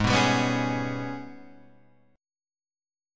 AbmM7bb5 chord